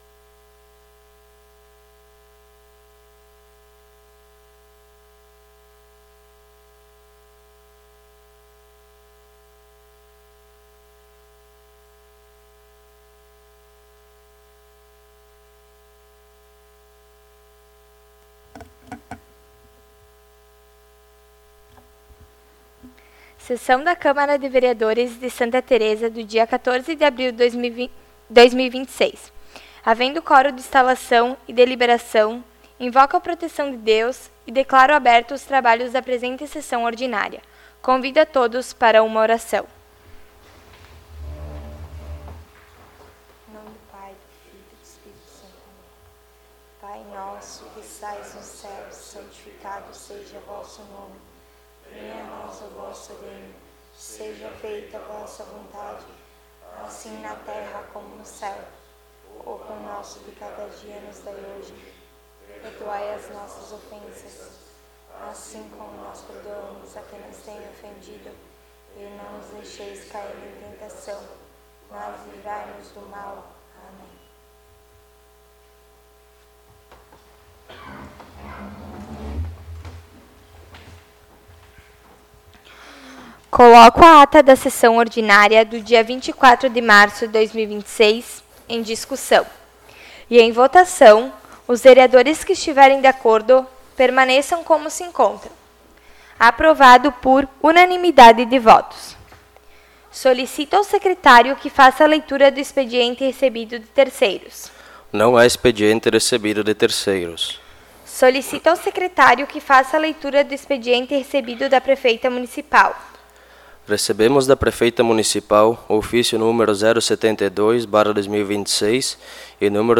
Áudio da Sessão